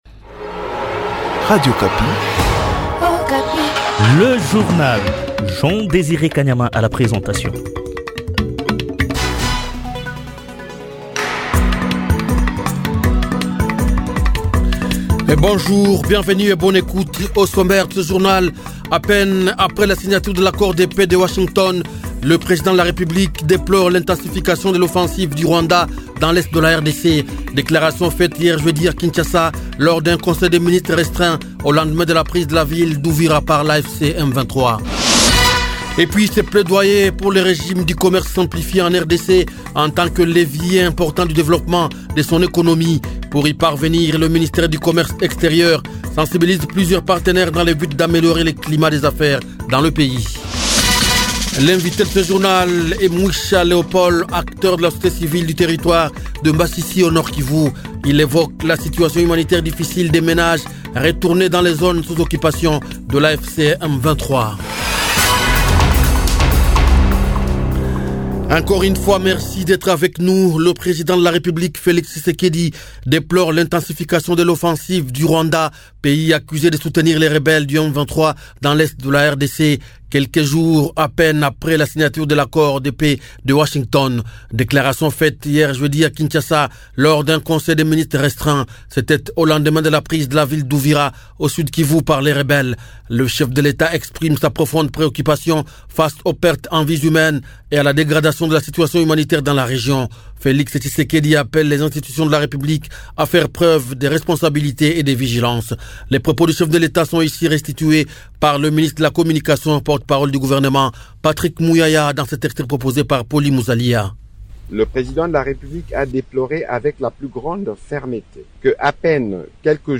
Journal Francais